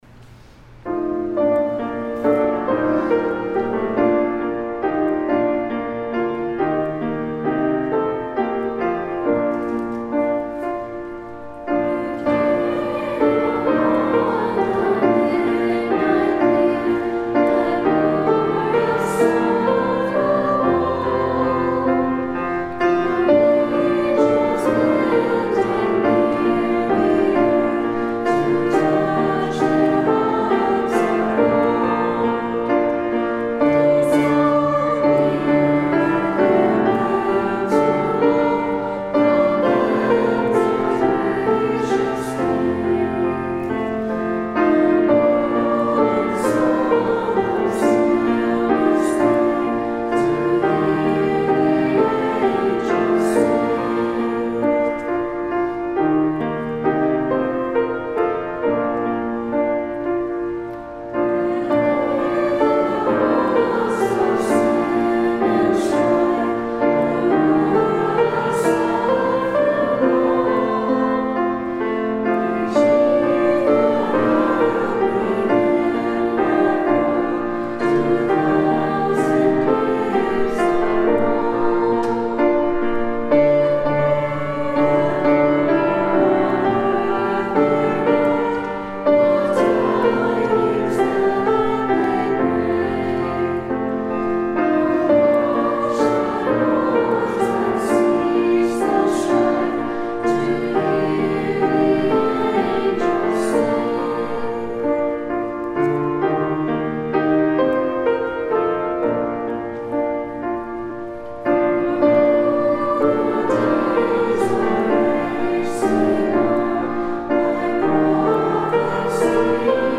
Musical Rendition & Lyrics
Music provided by members of the First Presbyterian Church Youth Group and Youth Choir